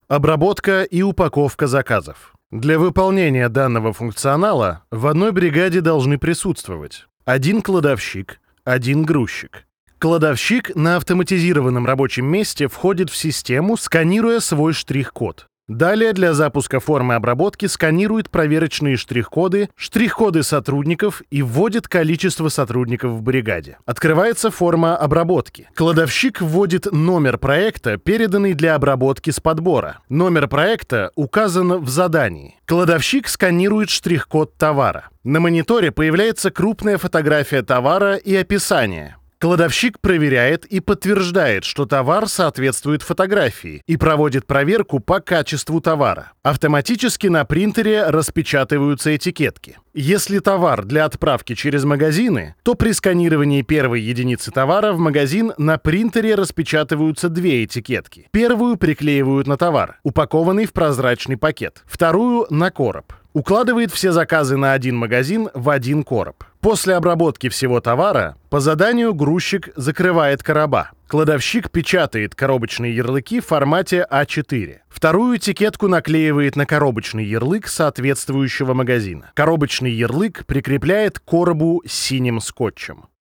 Муж, Инструкция/скринкаст/Средний
Живой, естественный голос с широким спектром возможностей для разных задач.
Акустически подготовленное помещение с качественным оборудованием.